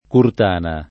curtana
curtana [ kurt # na ]